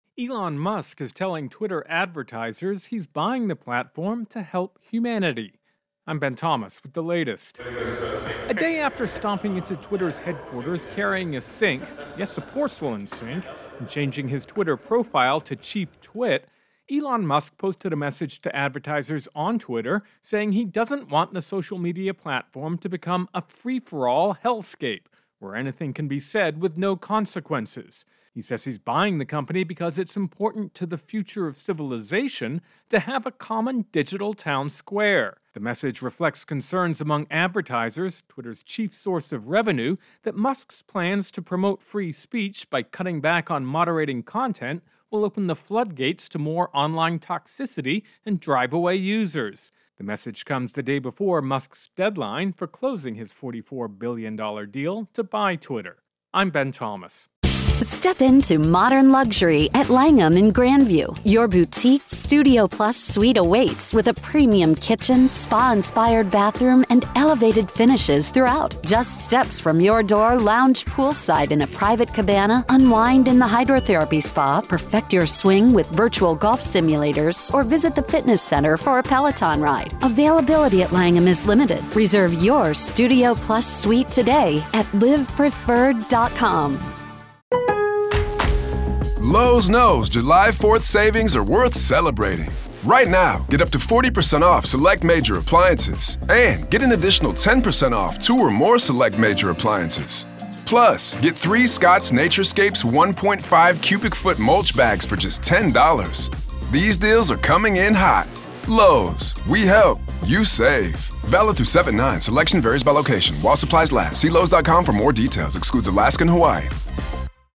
reports on Musk-Twitter.